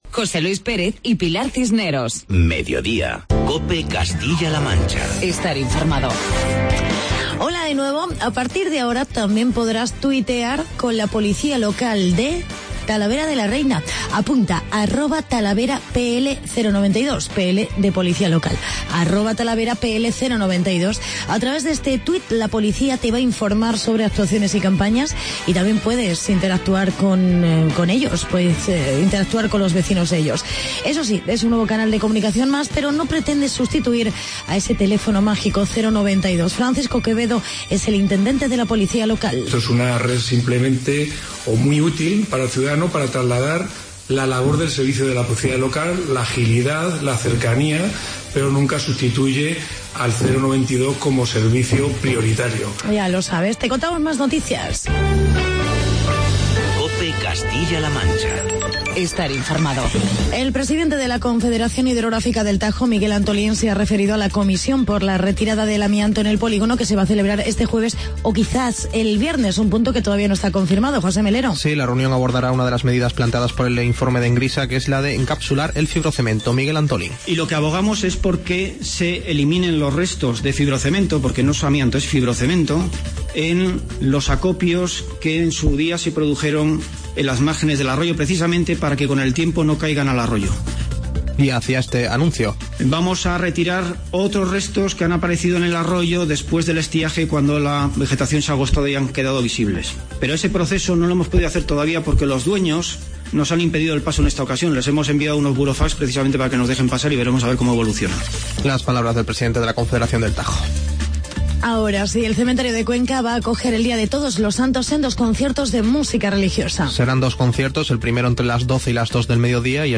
Actualidad y entrevista con el alcalde de Talavera de la Reina, Jaime Ramos, sobre la posibilidad de construir una gasolinera en la ciudad y ordenanzas fiscales.